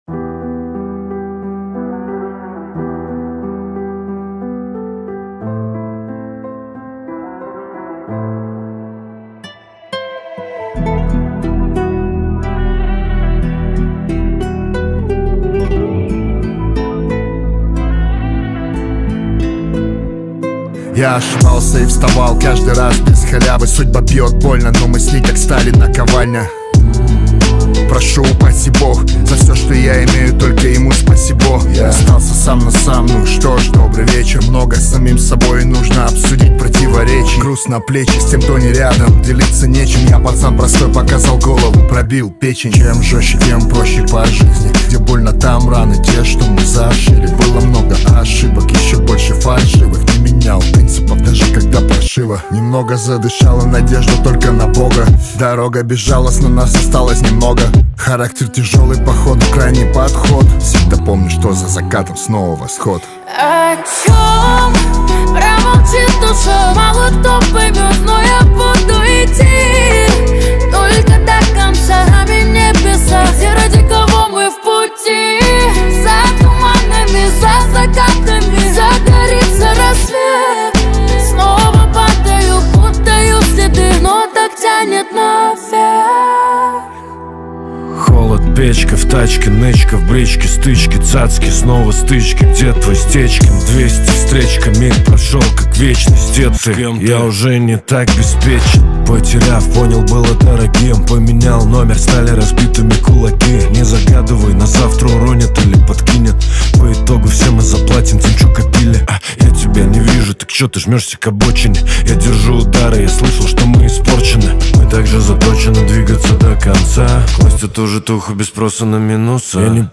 хип-хопа